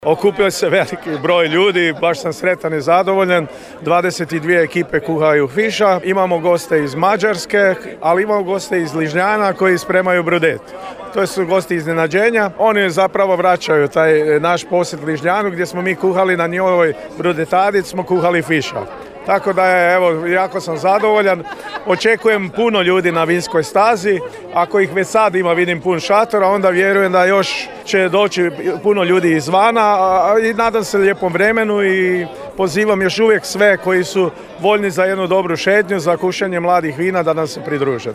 – rekao je načelnik Općine Virje Mirko Perok, koji je najbolje plasiranim ekipama dodijelio nagrade i pehare.